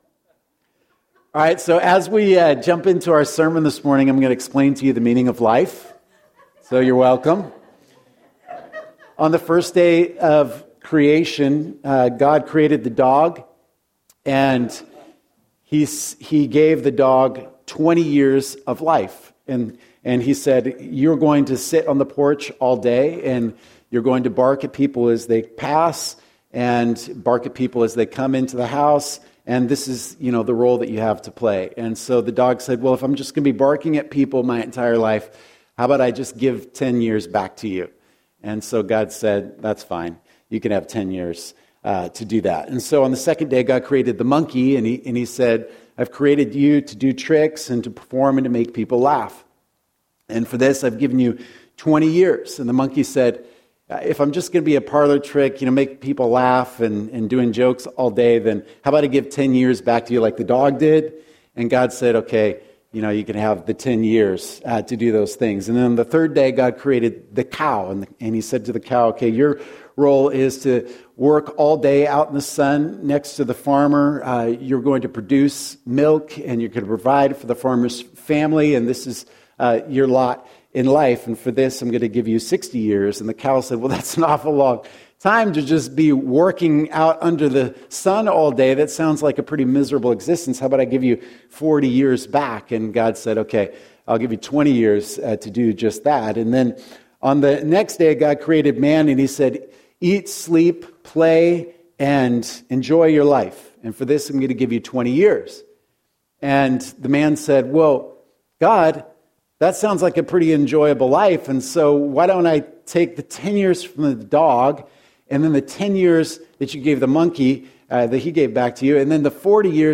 During this sermon series, we will be sharing them with you!